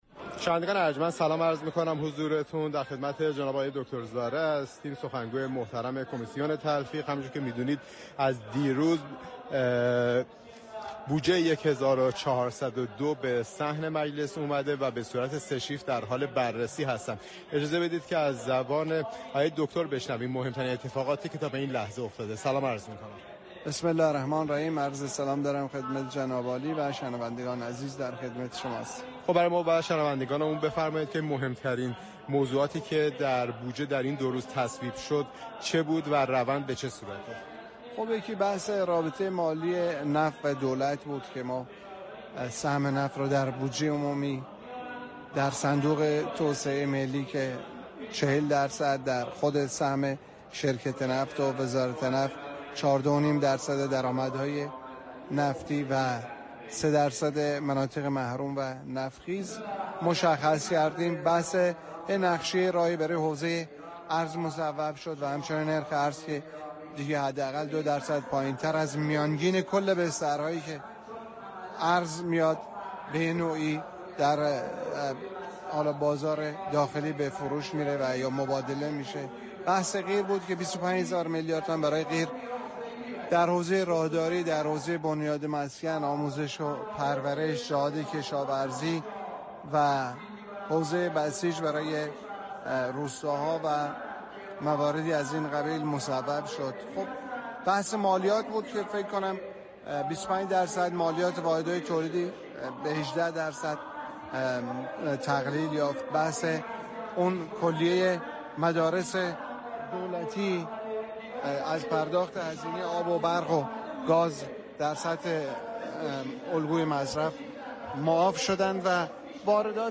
شخصیت مهم خبری: رحیم زارع سخنگوی كمیسیون تلفیق